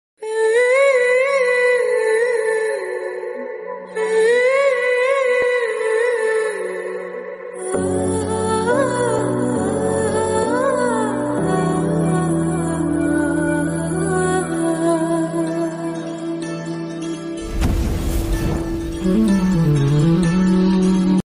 Love Feel Bgm Ringtone